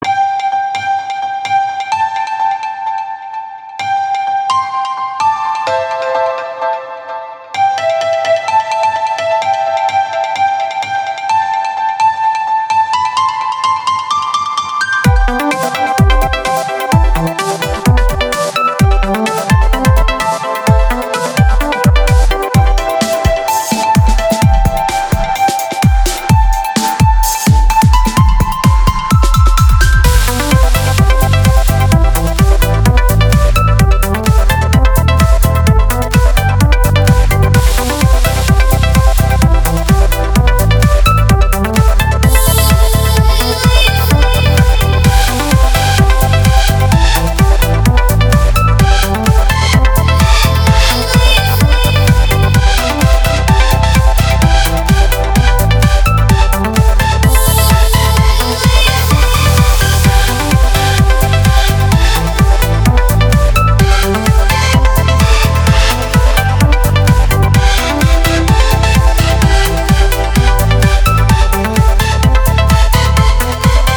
• Качество: 320, Stereo
Electronic
без слов
красивая мелодия
electro
колокольчики